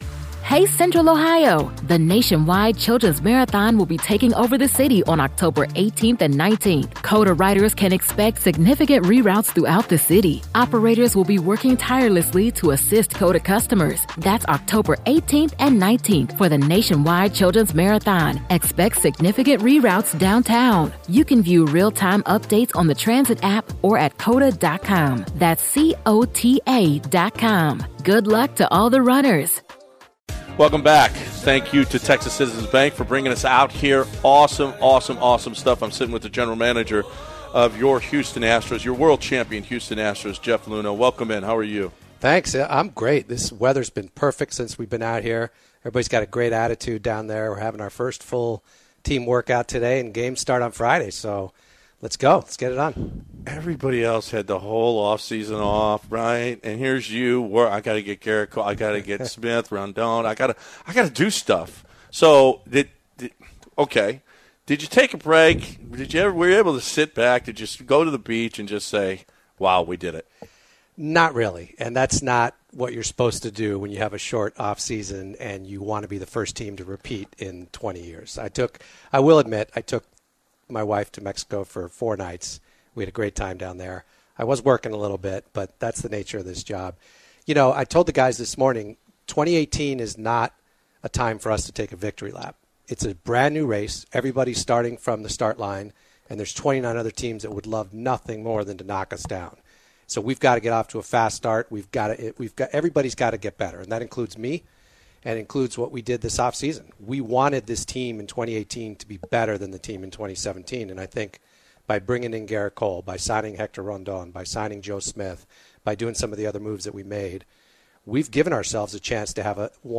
02/19/2018 Jeff Luhnow Spring Training Interview